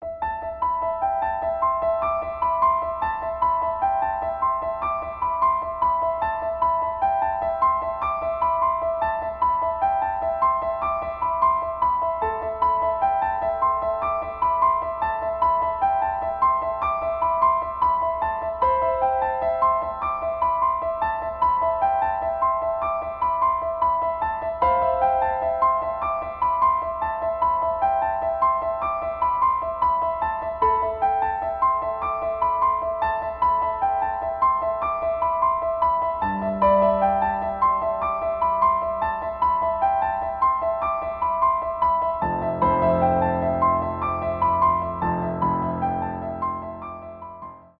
пианино
без слов
инструментальные